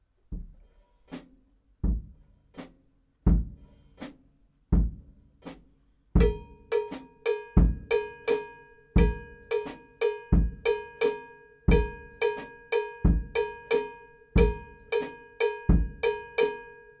Eine zweitaktige Grundfigur, ein Takt enthält zwei, einer drei Schläge.
So klingt die 3-2-Son Clave.
drei-zwei-son-clave.mp3